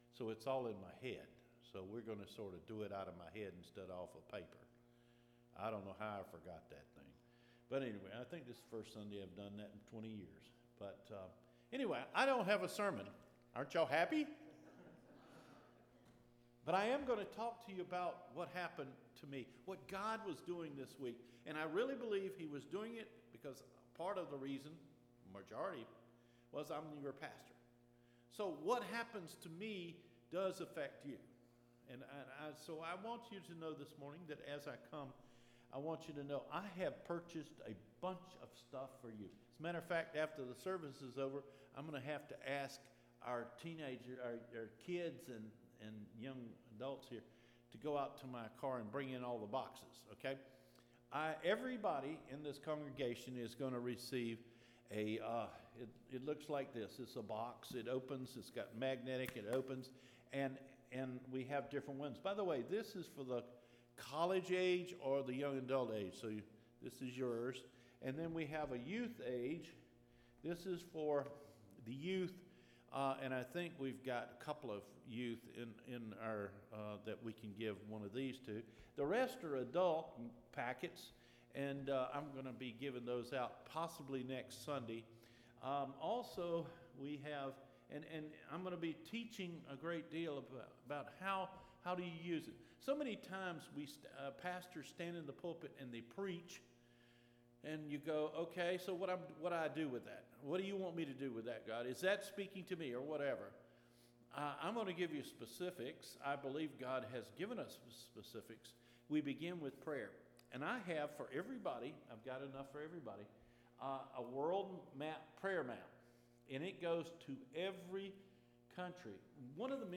MAY 30 SERMON – THE FAITH OF DISCIPLE